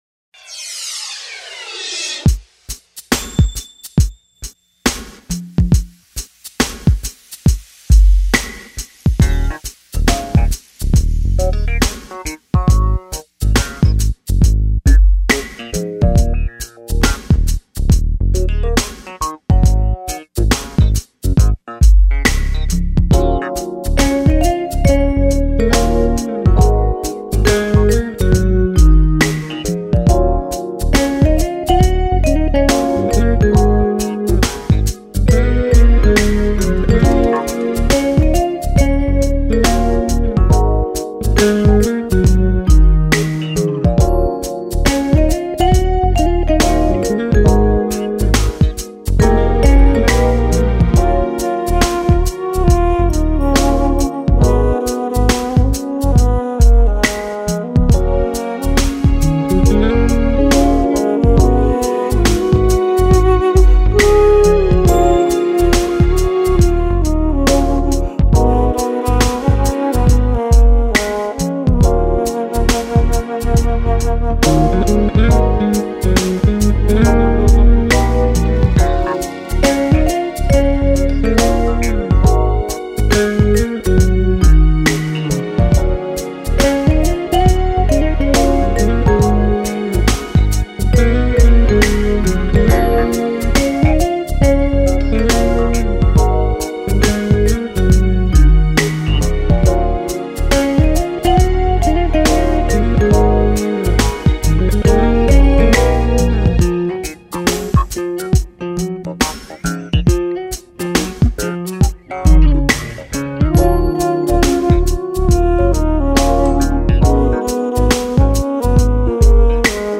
His music is soulful and contemporary.
six-string bassist
Genre : Smooth/Contemporary Jazz.